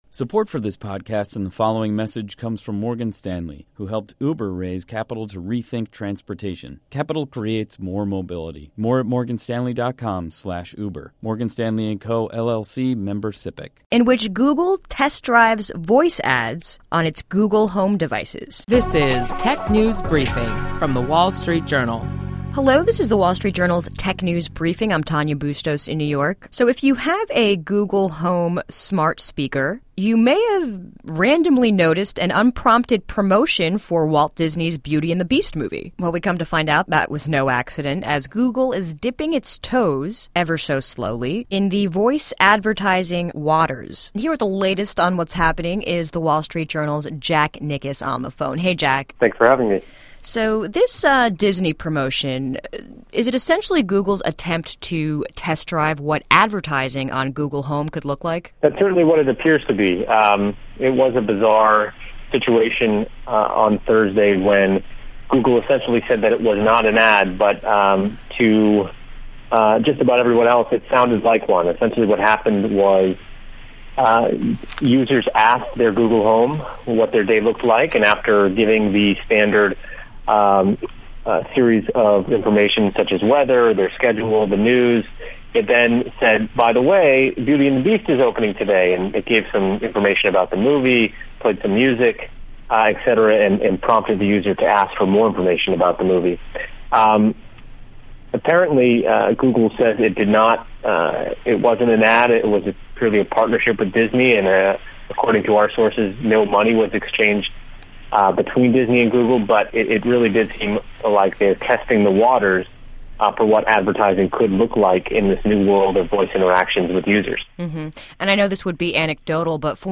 Stay informed on the latest trends with daily insights on what’s hot and happening in the world of technology. Listen to our reporters discuss notable company news, new tech gadgets, personal technology updates, app features, start-up highlights and more.